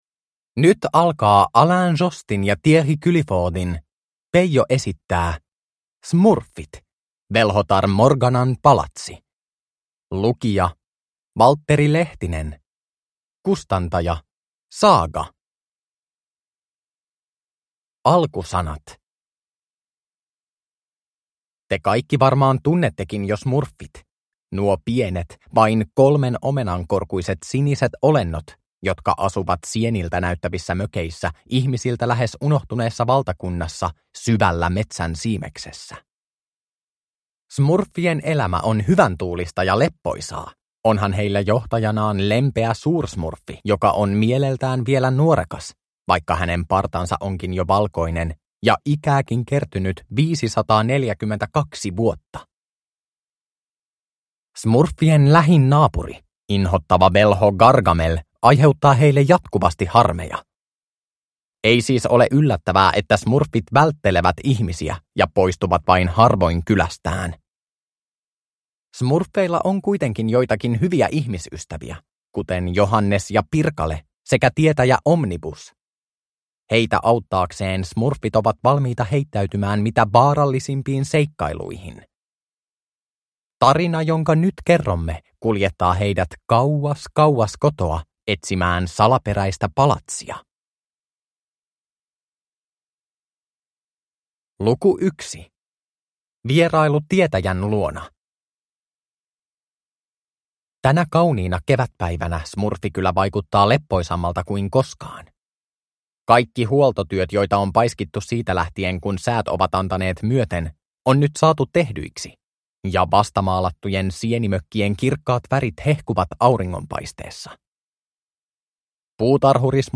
Smurffit - Velhotar Morganan palatsi (ljudbok) av Peyo